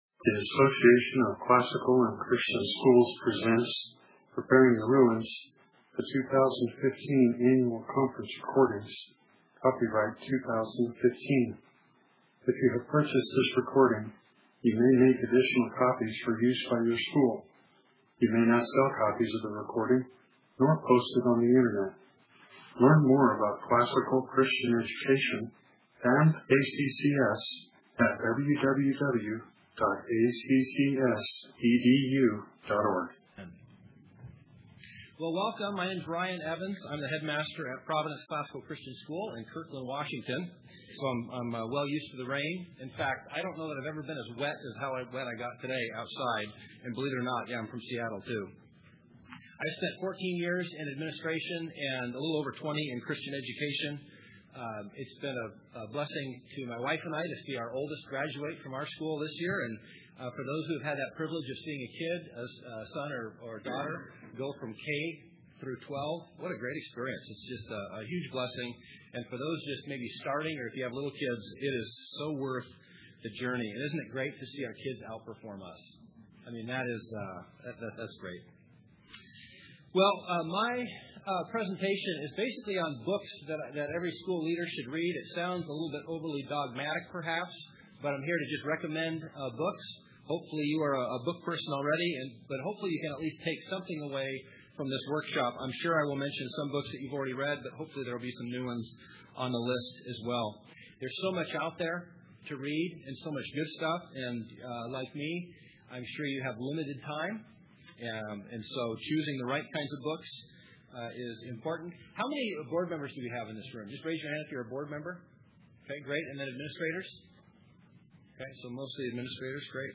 2015 Foundations Talk | 0:43:17 | All Grade Levels, Leadership & Strategic, Teacher & Classroom
In this workshop, we will examine additional “must read” books in the areas of board governance and leadership, Christian education, classical education, and worldview development.
Additional Materials The Association of Classical & Christian Schools presents Repairing the Ruins, the ACCS annual conference, copyright ACCS.